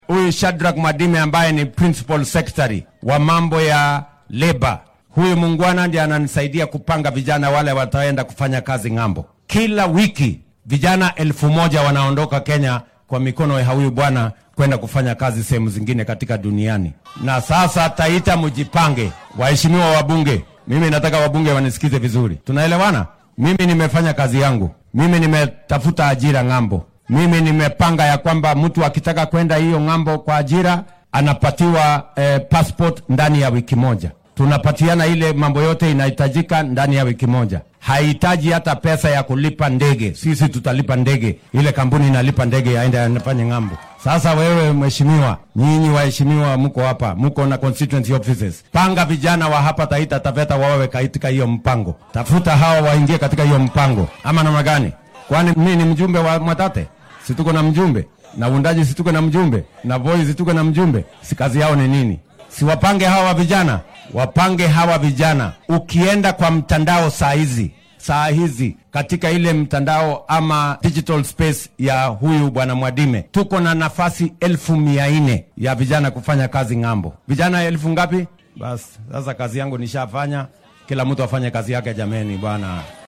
Madaxweynaha dalka William Ruto ayaa xilli uu maanta ku sugnaa deegaanka Mwatete ee ismaamulka Taita Taveta ee gobolka Xeebta sheegay in kenyaanka fursadaha shaqo ka hela dibadda muddo toddobaad ah loogu soo saari doono baasaboorka.